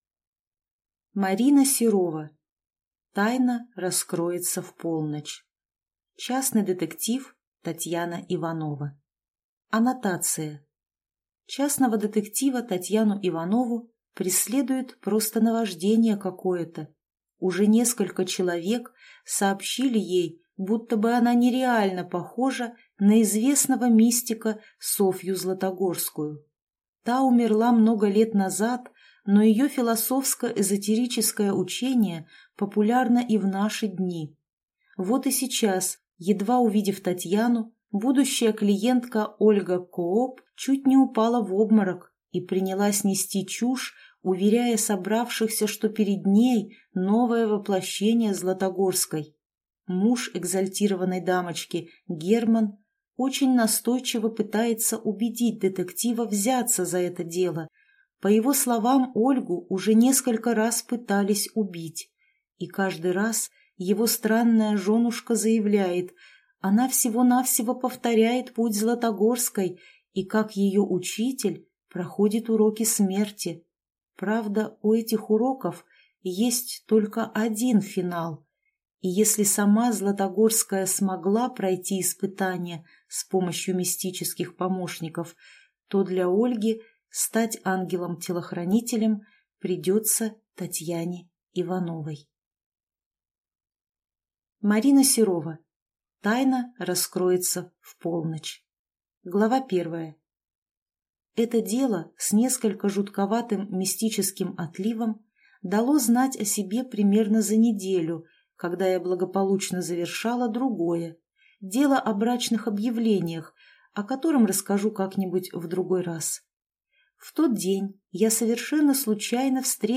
Аудиокнига Тайна раскроется в полночь | Библиотека аудиокниг